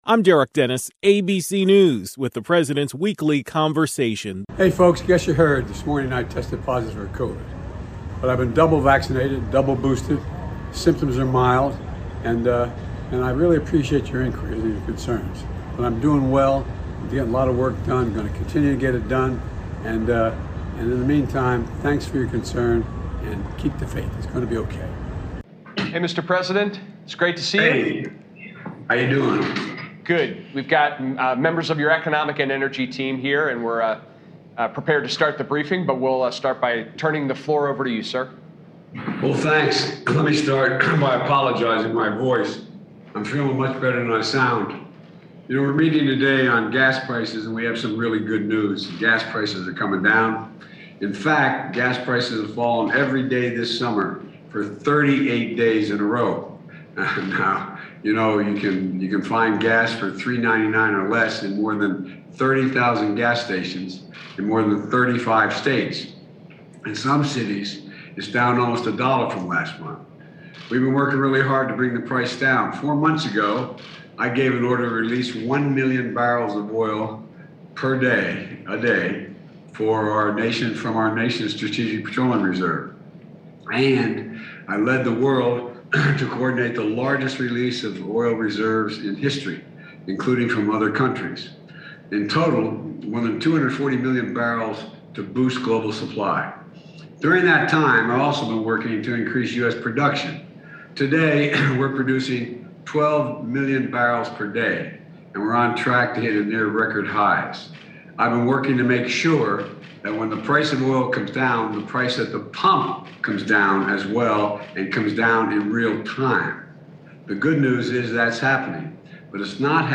President Biden delivered remarks remotely, at an Economic Briefing.